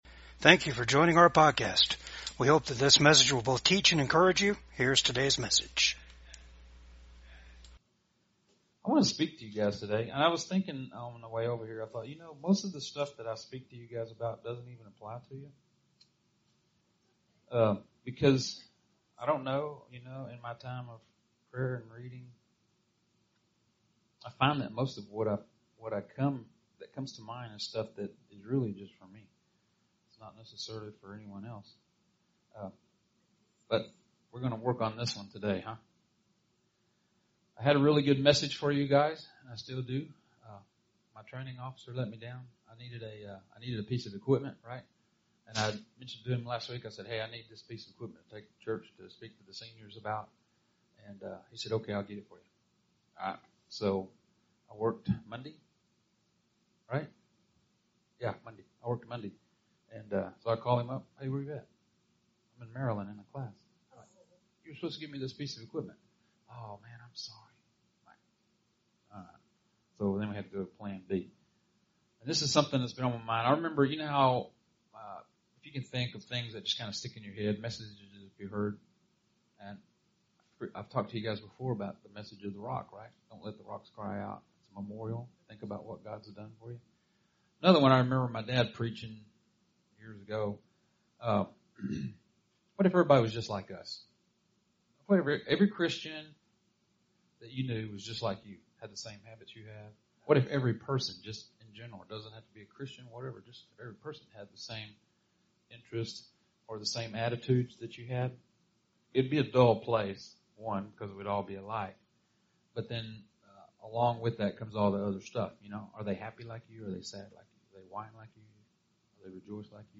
Service Type: VCAG WEDNESDAY SERVICE